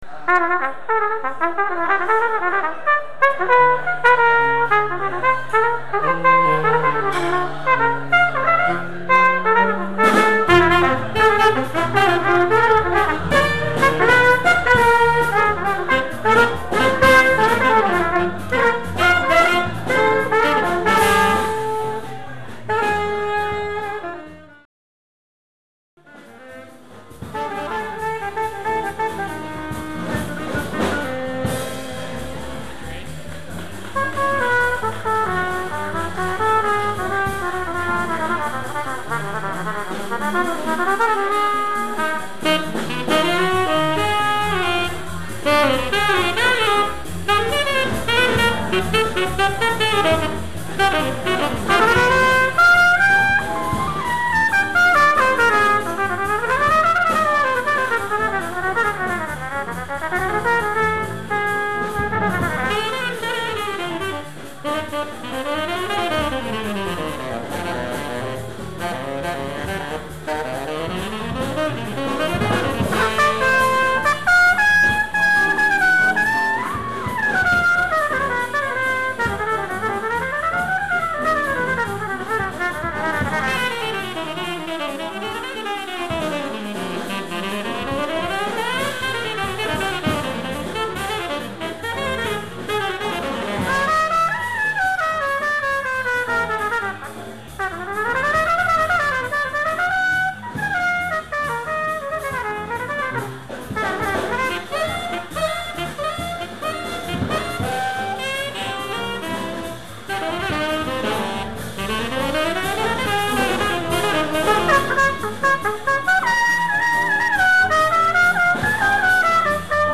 tenor saxophone
alto
piano
bass
drums.